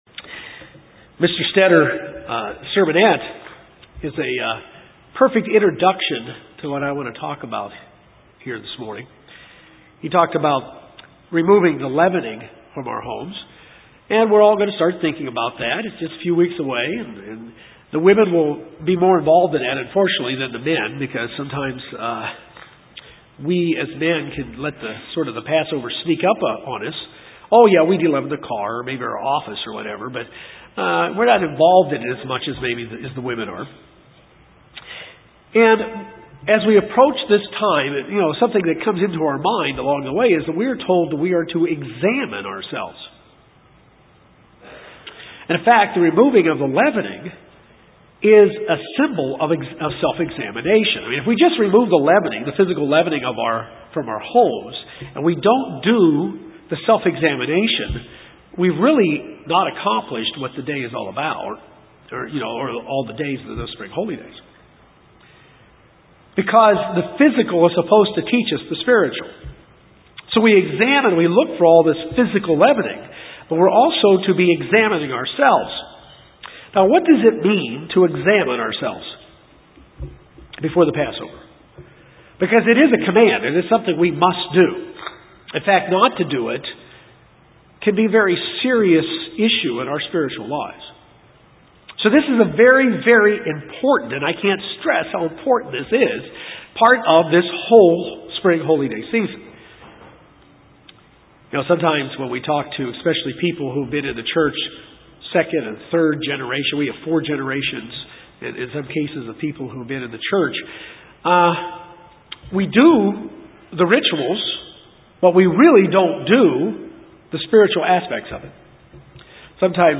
This sermon gives 3 questions to ask ourselves during this time of year when we are instructed to examine ourselves in preparation for the Passover and the Days of Unleavened Bread. It is important that we take this seriously and do a proper examination so that we will discern the Lord’s body.